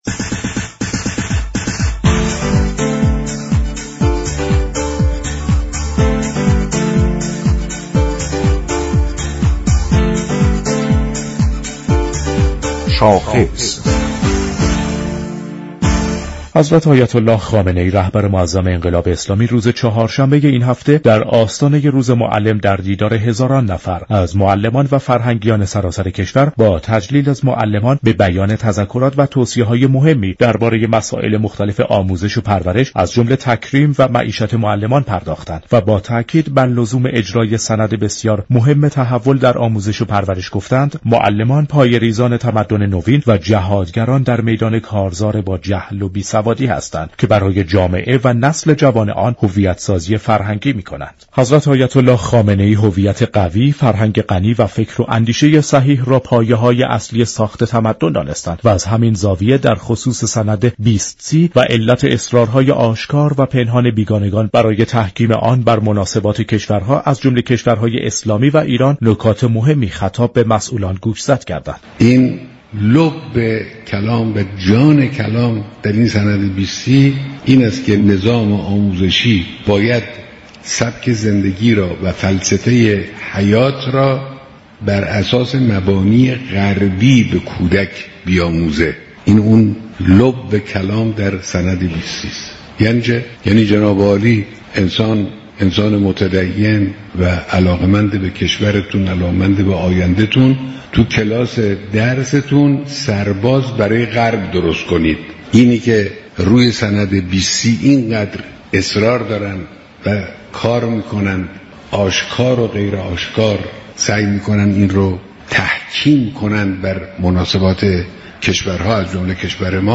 دكتر مهدی نوید ادهم دبیر كل شورای عالی آموزش و پرورش در گفت و گو با برنامه "رویدادهای هفته" درباره دیدار اخیر جمعی از معلمان با مقام معظم رهبری گفت: رهبر معظم انقلاب در یازدهم اردیبهشت در بخشی از سخنان گوهربارشان به سند تحول بنیادین آموزش و پرورش اشاره كردند.
این گفت و گو را در ادامه باهم می شنویم.